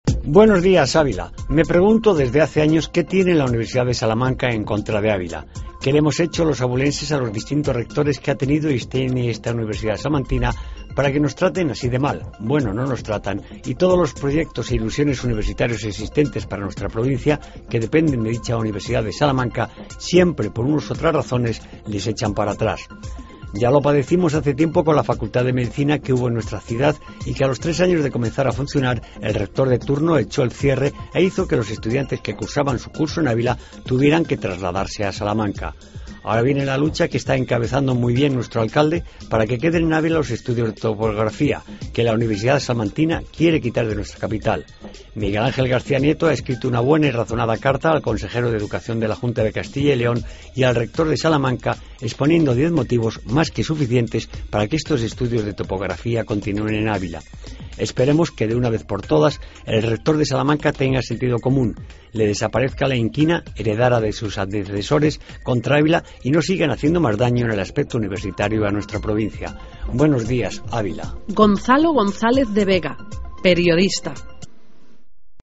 AUDIO: Comentario de actualidad